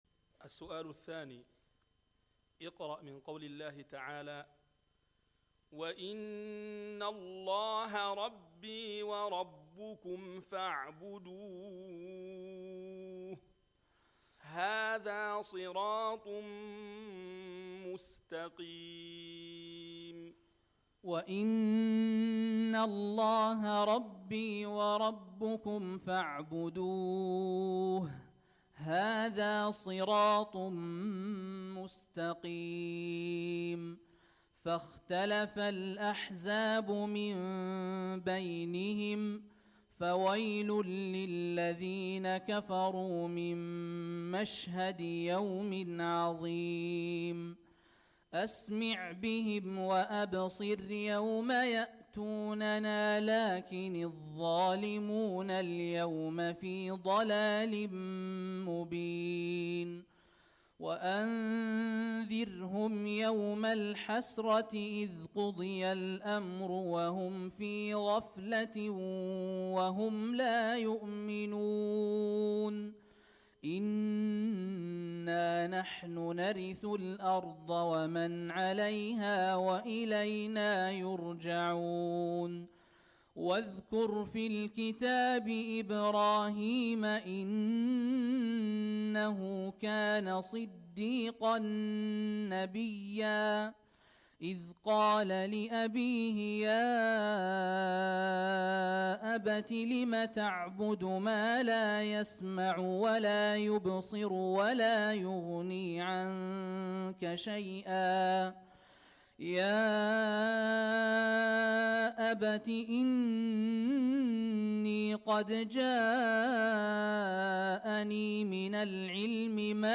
تلاوة طيبة لإمام المسجد الحرام الشيخ الوليد الشمسان من مشاركته قبل 13 عاماً في مسابقة الكويت الدولية للقرآن الكريم > جهود أئمة الحرمين > المزيد - تلاوات الحرمين